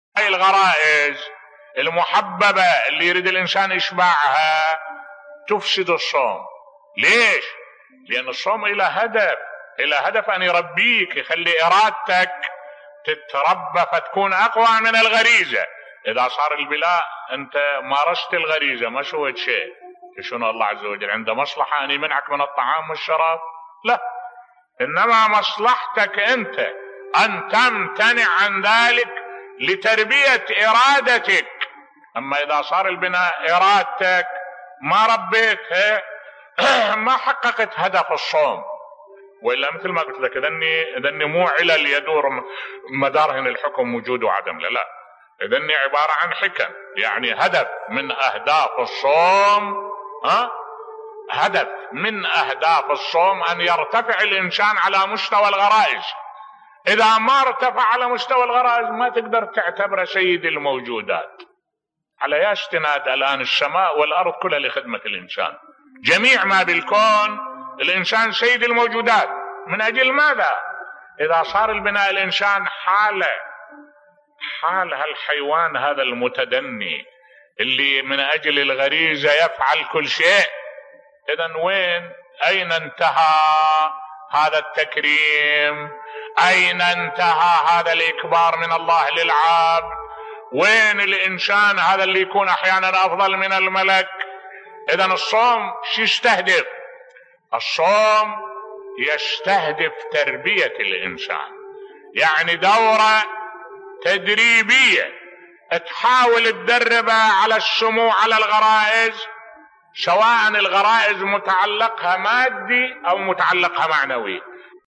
ملف صوتی ما الهدف من الصوم بصوت الشيخ الدكتور أحمد الوائلي